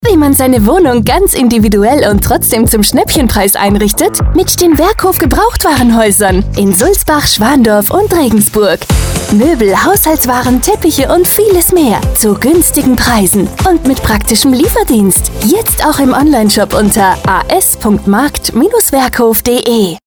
Unser aktueller Radiospot
Radio-Werbespot Werkhof Amberg-Sulzbach